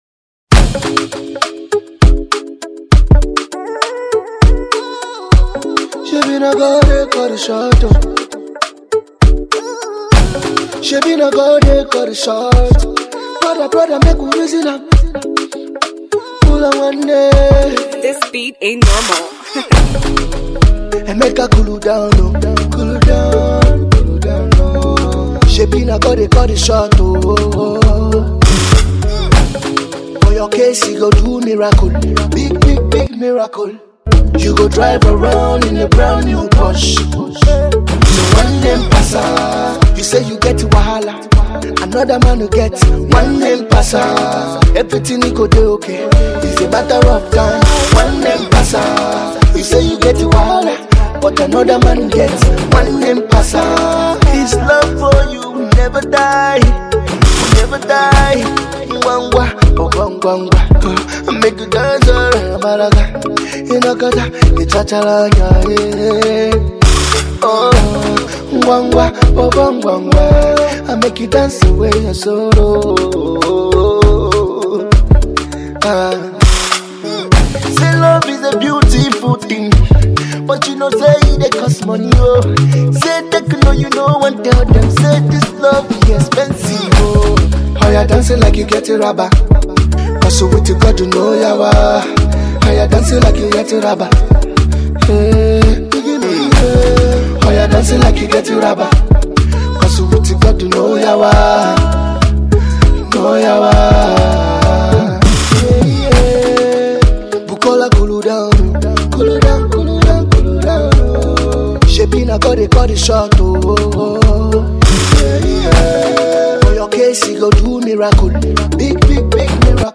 his own version